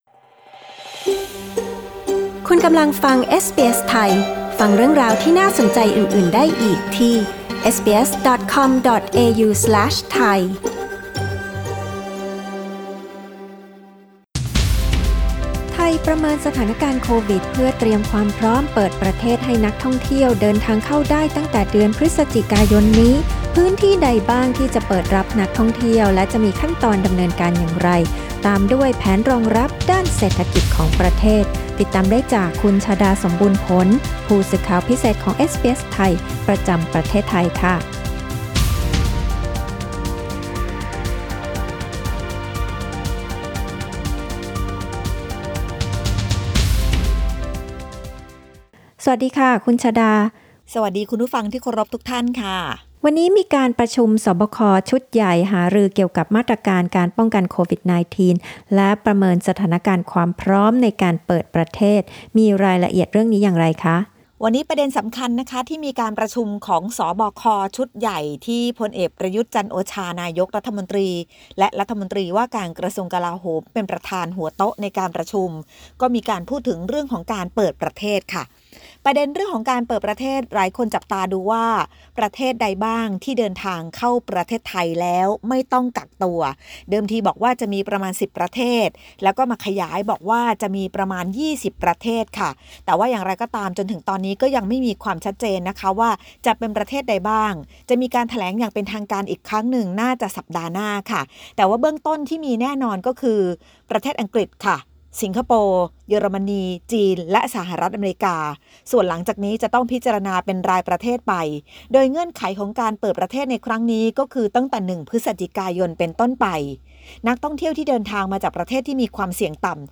ฟังรายงานข่าว